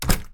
door-close-1.ogg